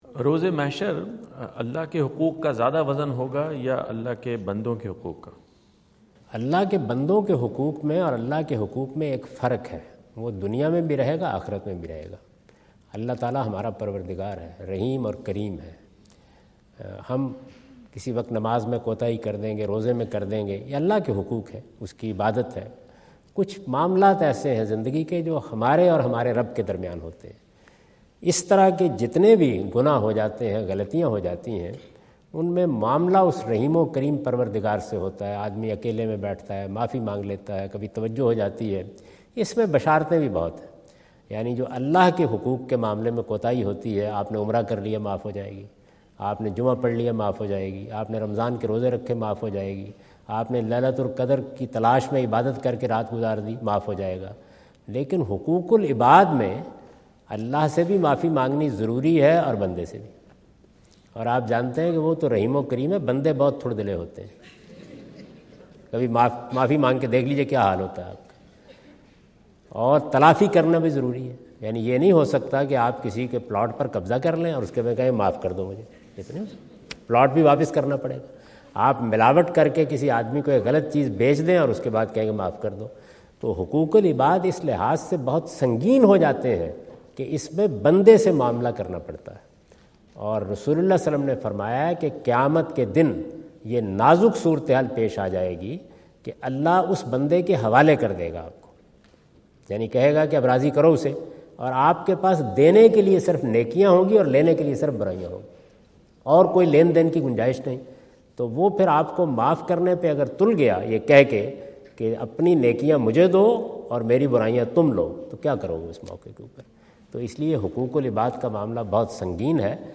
Category: English Subtitled / Questions_Answers /
Javed Ahmad Ghamidi answer the question about "Significance of Rights of God and Mankind" asked at North Brunswick High School, New Jersey on September 29,2017.